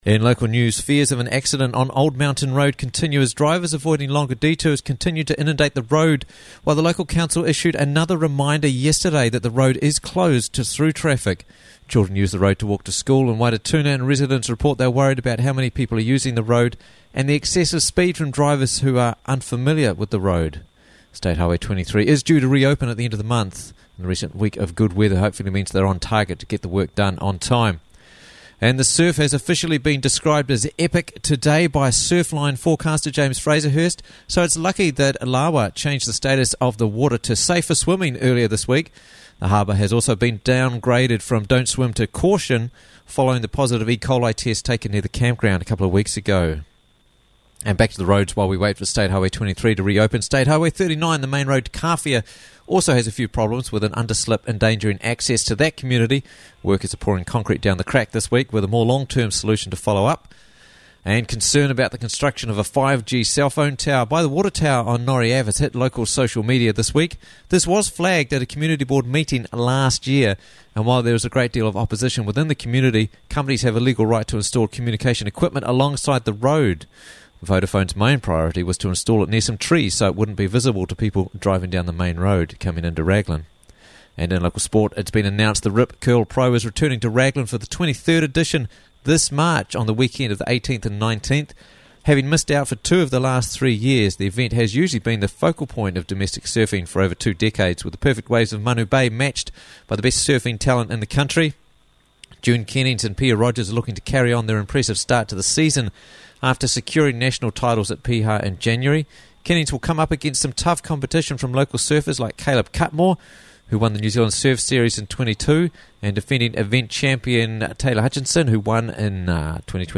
Raglan News Wednesday 22nd Feb 2023 - Raglan News Bulletin